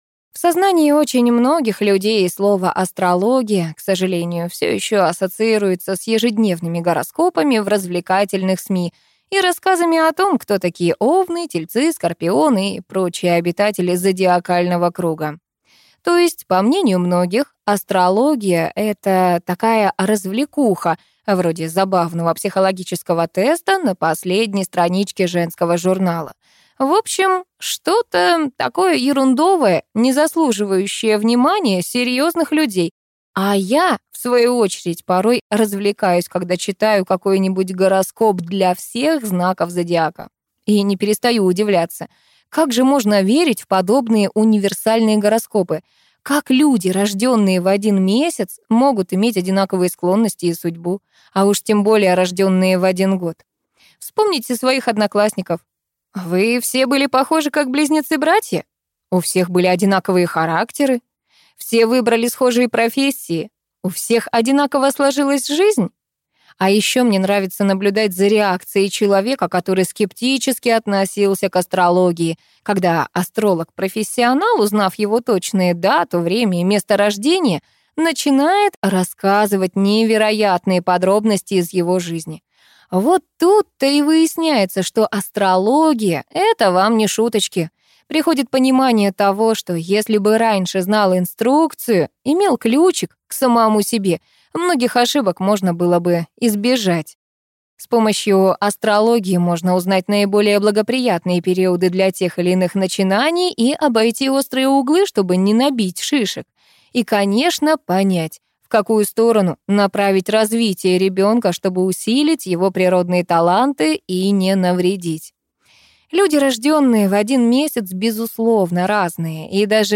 Аудиокнига Маленький космос. Адекватная астрология для родителей | Библиотека аудиокниг